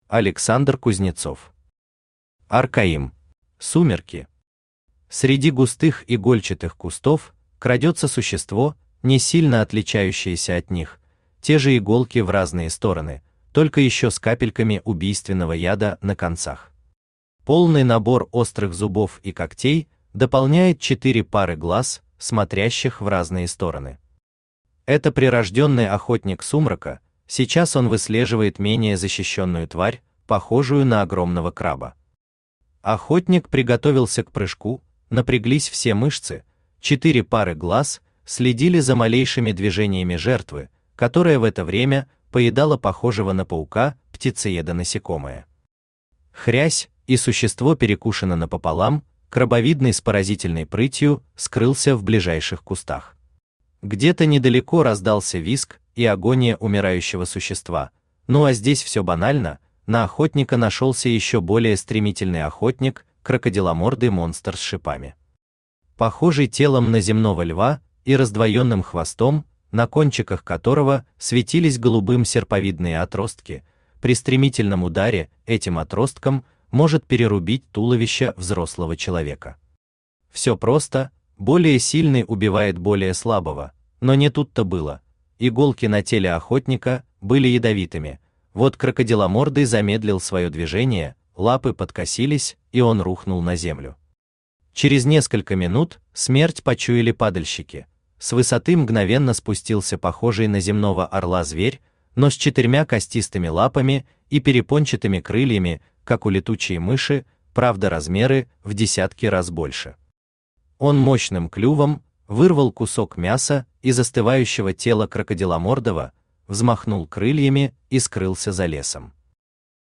Аудиокнига Аркаим | Библиотека аудиокниг
Aудиокнига Аркаим Автор Александр Евгеньевич Кузнецов Читает аудиокнигу Авточтец ЛитРес.